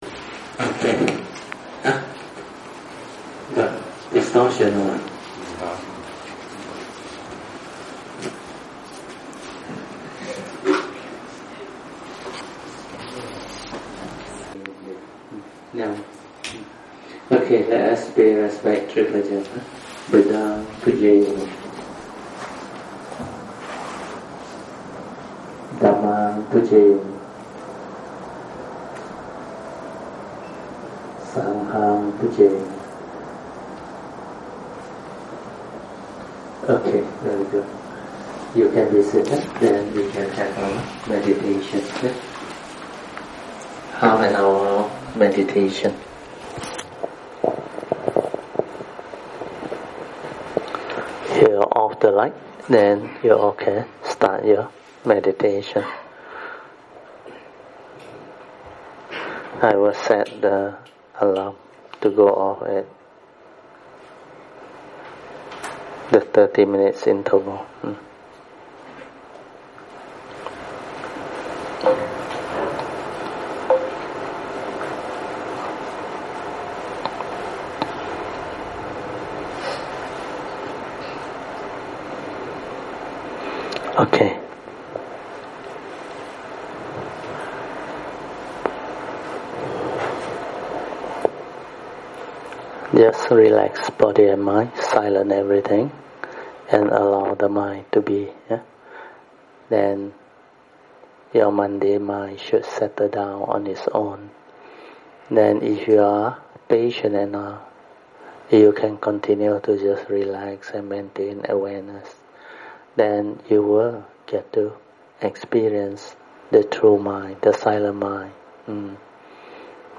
Thursday Class